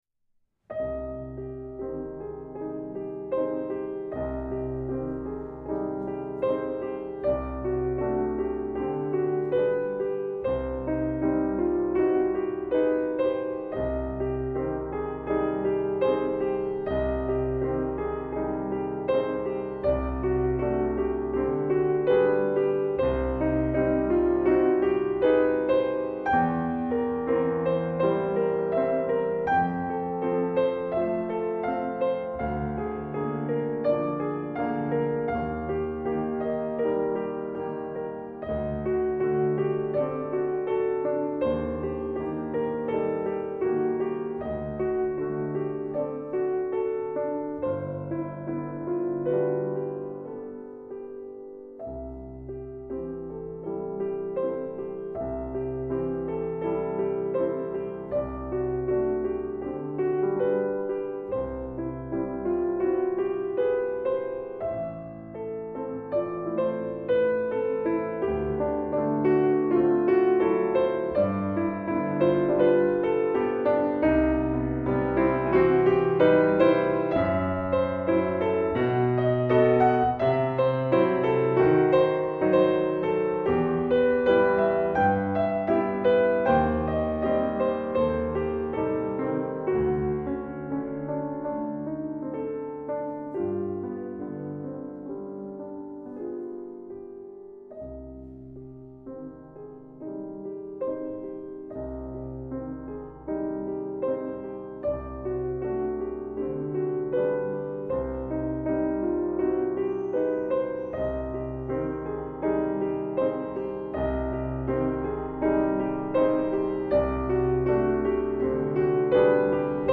Pianoforte